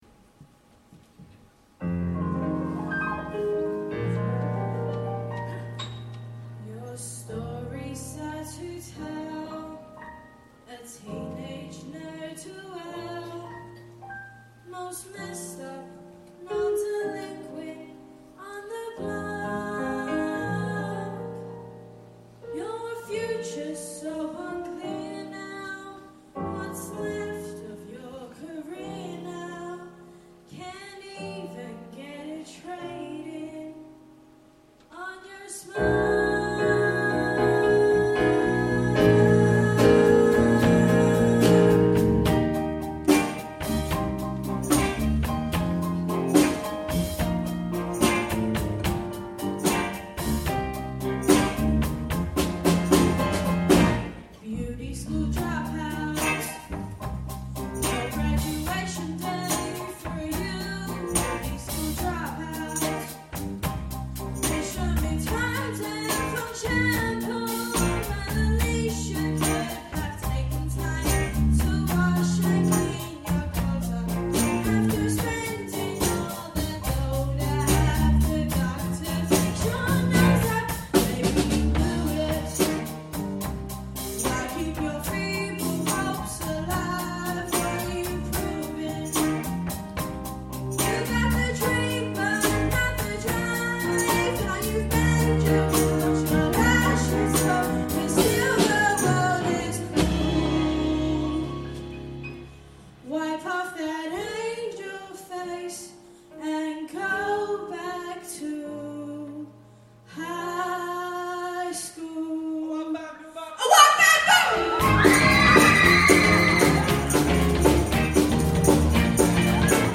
Musical Theatre
Performed at the Autumn Concert, November 2014 at the Broxbourne Civic Hall.